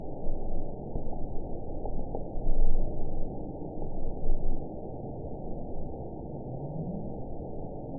event 922891 date 05/01/25 time 17:57:36 GMT (7 months ago) score 9.35 location TSS-AB06 detected by nrw target species NRW annotations +NRW Spectrogram: Frequency (kHz) vs. Time (s) audio not available .wav